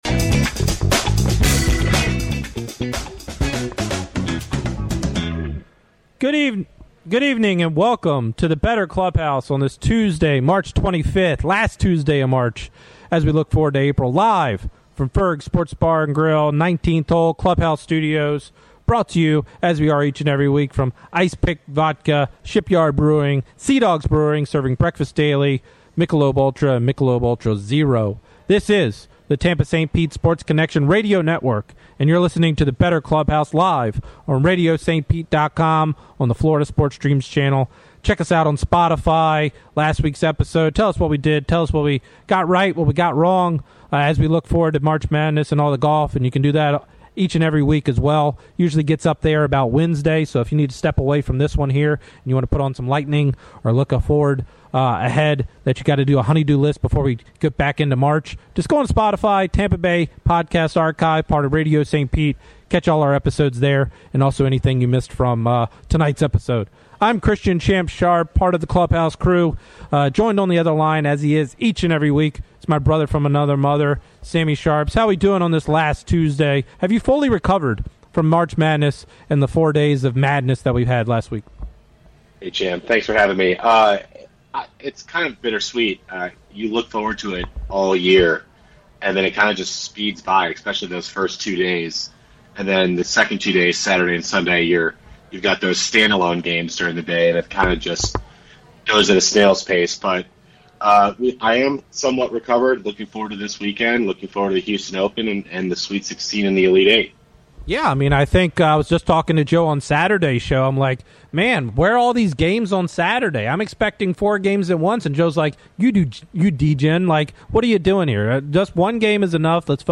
"The Bettor Clubhouse" 3-25-25 Live from Ferg's Tuesdays 7pm ET